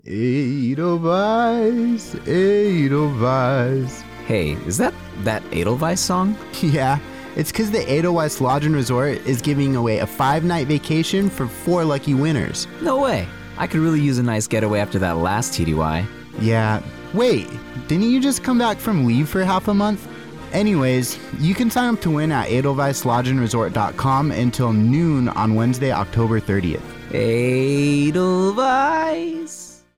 A 30 second radio spot for the Edelweiss Lodge and Resort giveaway for AFN Spangdahlem radio.